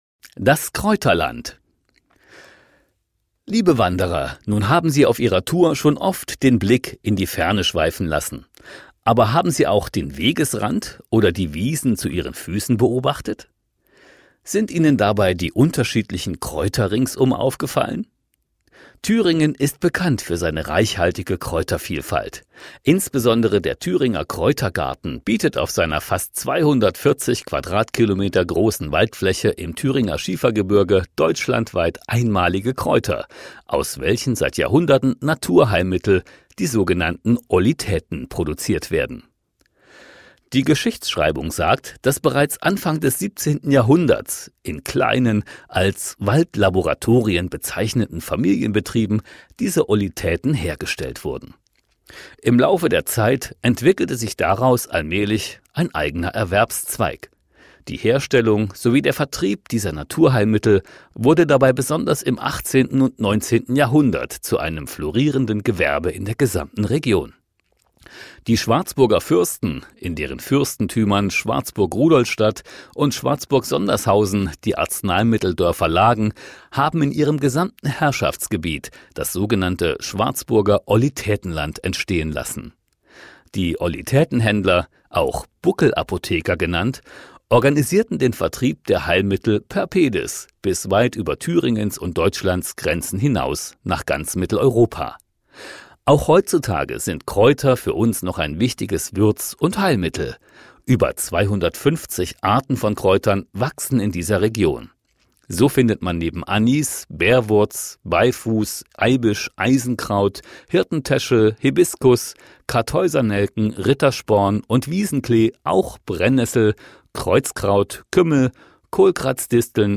Audio-Guide